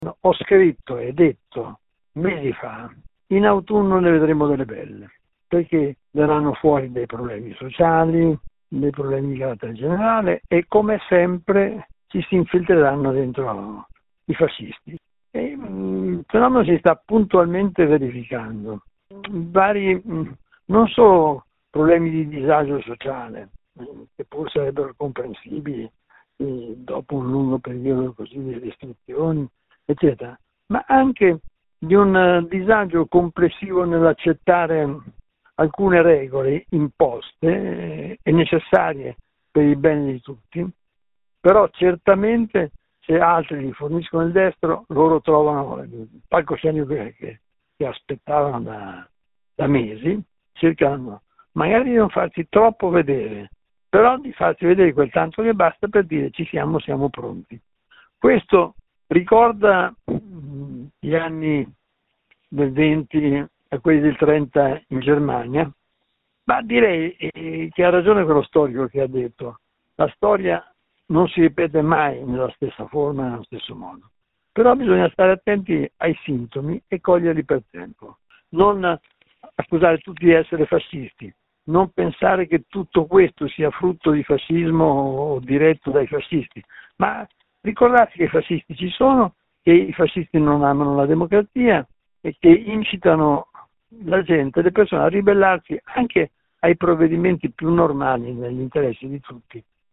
Abbiamo chiesto a Carlo Smuraglia, presidente emerito dell’Anpi, se si aspettava questa deriva e se c’è qualche somiglianza con il ventennio: